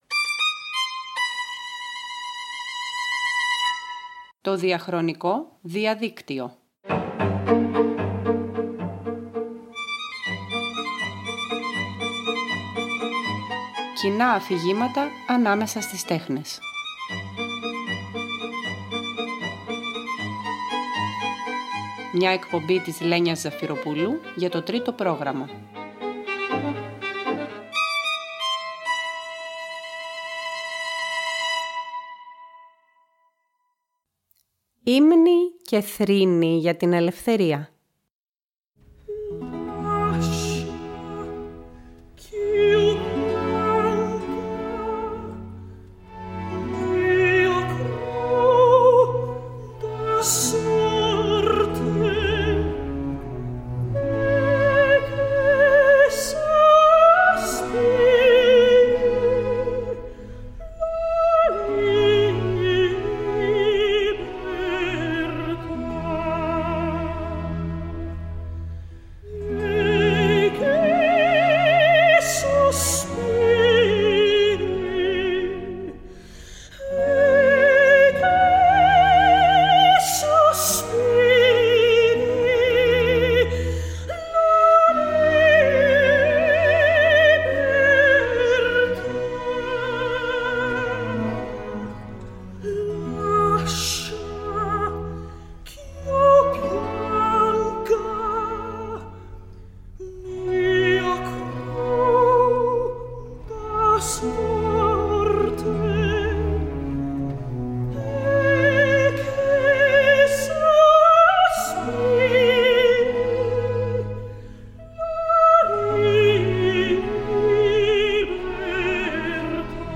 Το 1943 ο Francis Poulenc γράφει, πάνω σε στίχους του Paul Éluard την καντάτα Figure Humaine, ένα πανδύσκολο έργο για 12φωνη χορωδία που θα θεωρηθεί το αριστούργημά του.
Η καντάτα κλείνει με τον περίφημο ύμνο στην ελευθερία του Ελουάρ και μ’ ένα υπεράνθρωπο κόντρα μι στην πρώτη σοπράνο, προς δόξαν της ελευθερίας, της ίσως πιο περιμάχητης και φευγαλέας θεάς τ